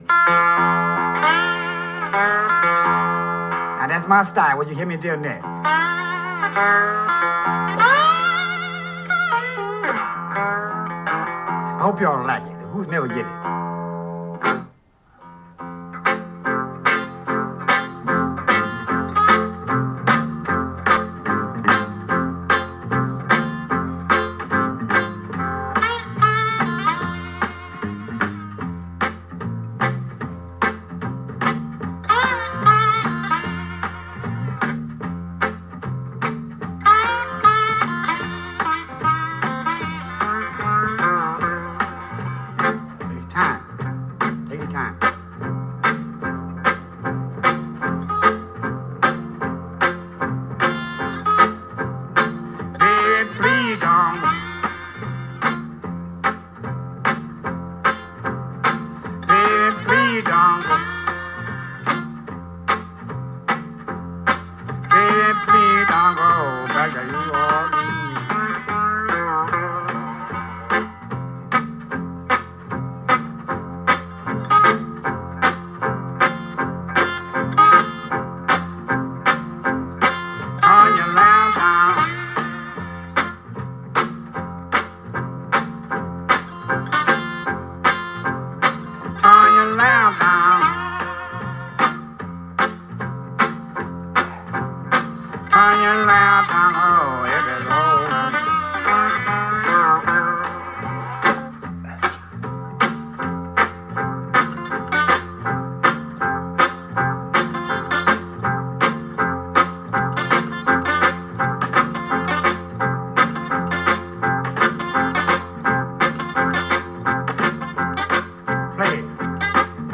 It’s his version of a blues classic that has the phrase,